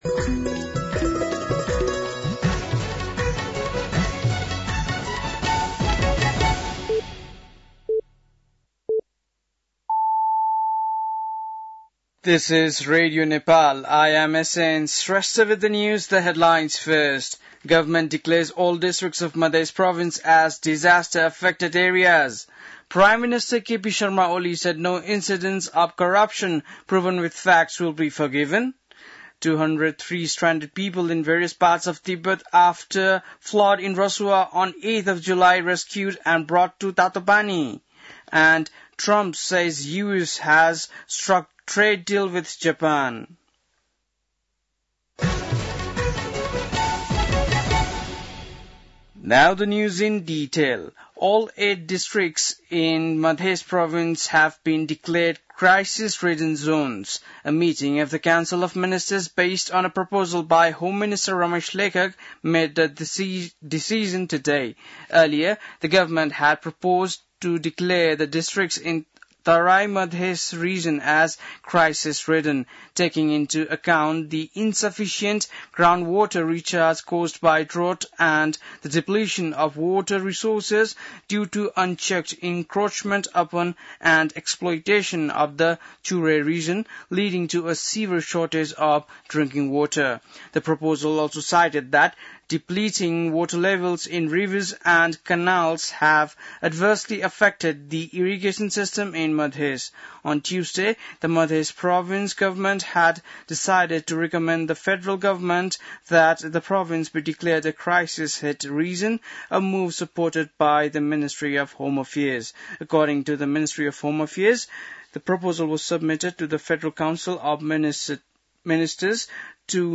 बेलुकी ८ बजेको अङ्ग्रेजी समाचार : ७ साउन , २०८२